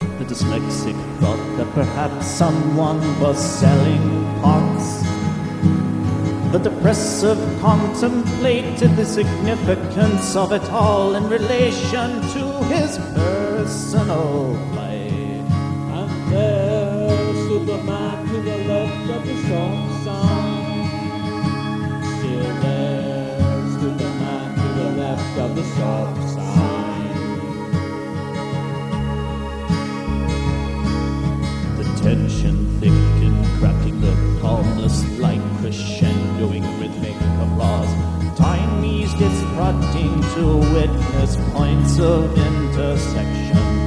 dark ballad
backing vocals
acoustic guitar